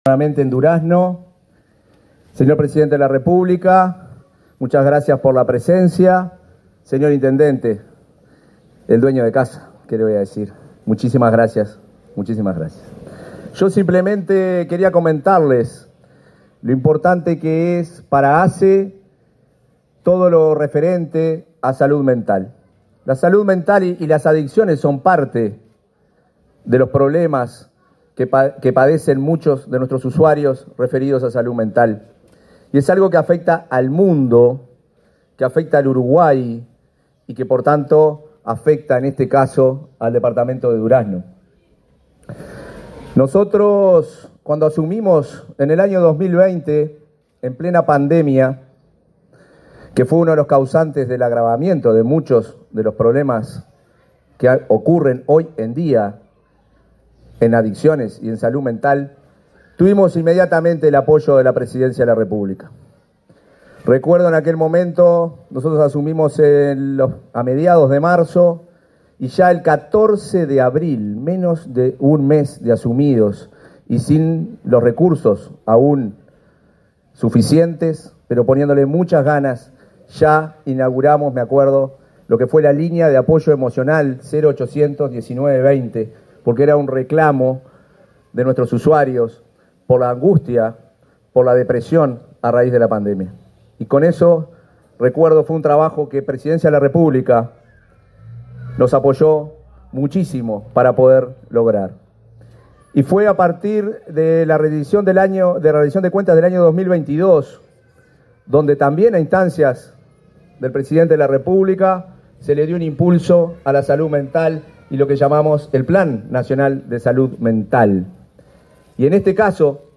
Palabras del presidente de ASSE, Marcelo Sosa
Este 22 de noviembre fue inaugurado el Centro Residencial Durazno de la Red Nacional de Drogas, con la presencia del presidente Luis Lacalle Pou. La obra fue impulsada por Presidencia de la República, la Intendencia de Durazno, la Administración de los Servicios de Salud del Estado (ASSE) y la Junta Nacional de Drogas.